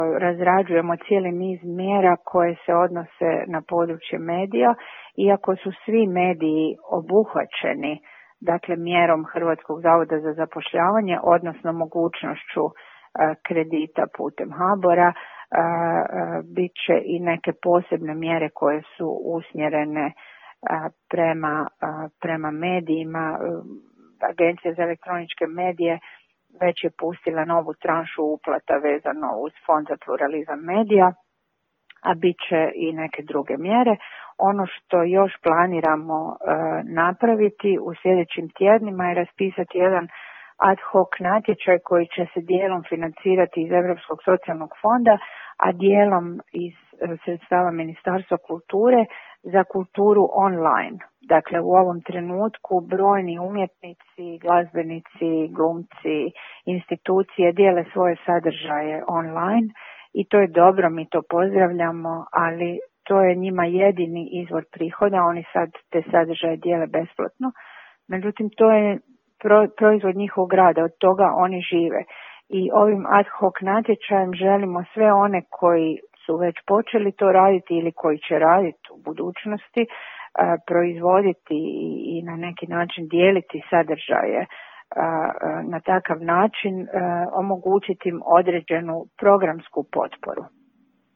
Ministrica kulture Nina Obuljen Koržinek izjava o mjerama za medije.